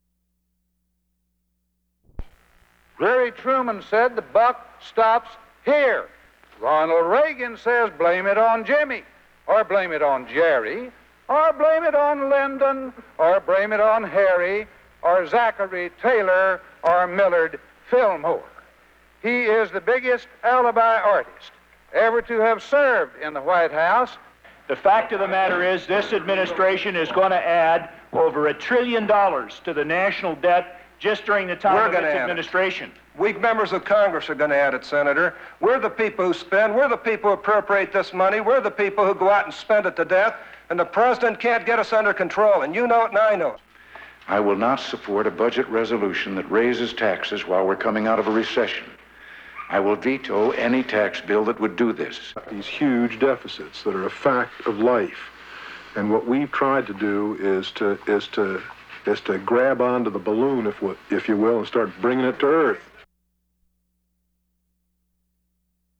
Debate on the budget
Congressman Jim Wright (D-TX), Senator Don Riegle (D-MI), Senator Bob Dole (R-KS), President Ronald Reagan, and Senator Lowell Weicker (R-CT) debate the federal budget.
Broadcast on CBS-TV (Sunday Morning), May 22, 1983.